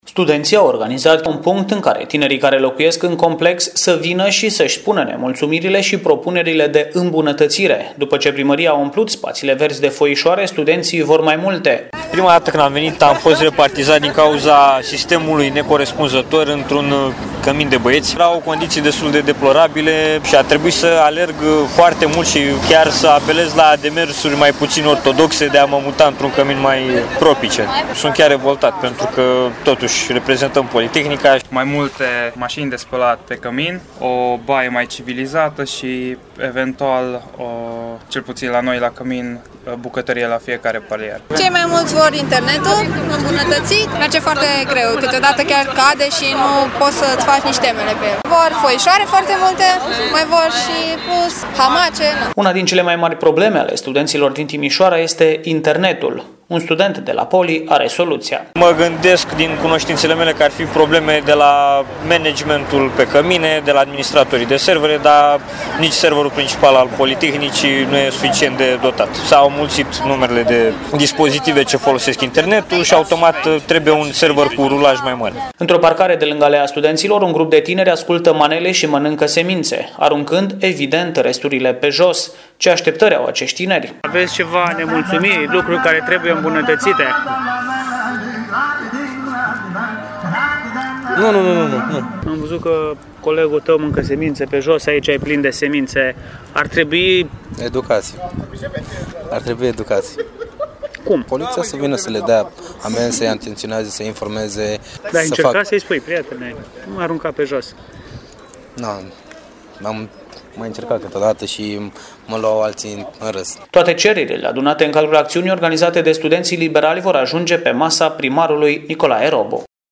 a stat de vorbă cu studenții